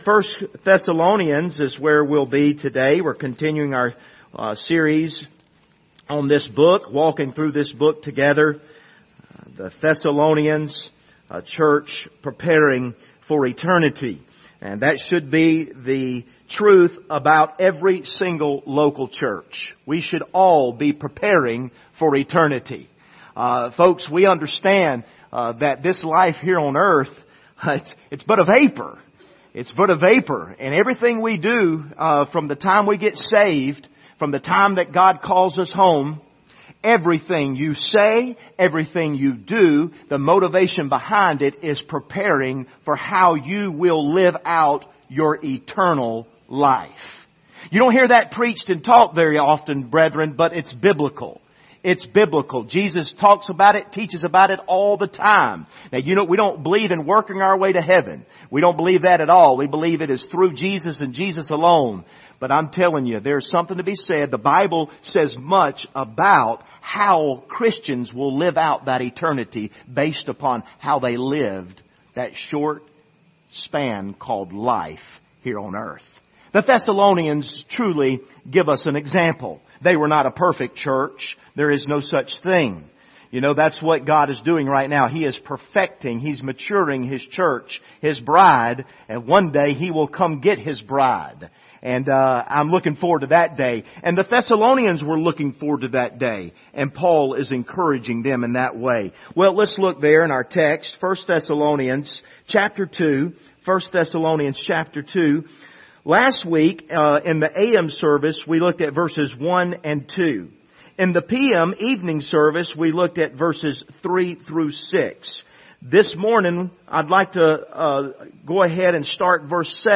Passage: 1 Thessalonians 2:7-12 Service Type: Sunday Morning